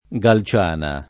Galciana [ g al ©# na ]